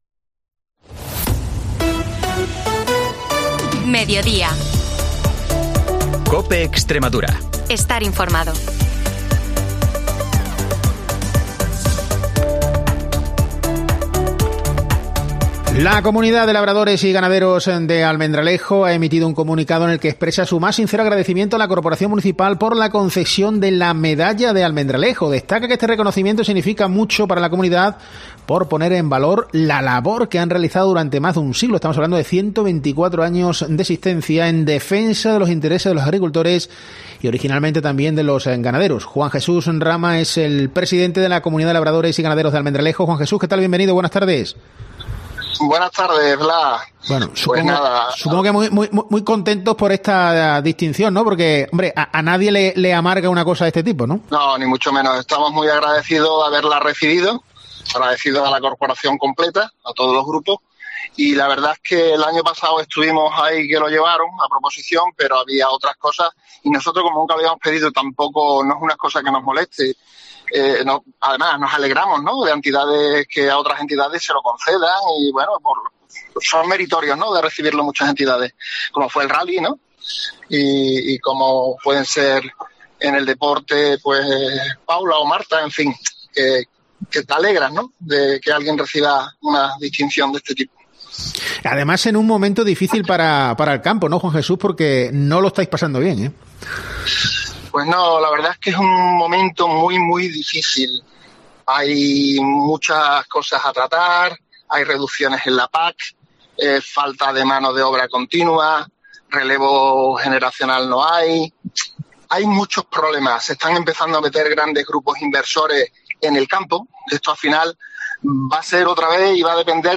Información y entrevistas de Almendralejo-Tierra de Barros y Zafra-Río Bodión, de lunes a jueves, de 13.50 a 14 horas